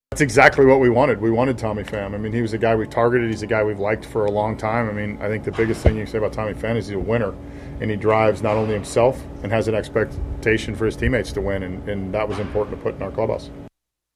Manager Derek Shelton says Pham brings the skills and the attitude that the Pirates desperately need.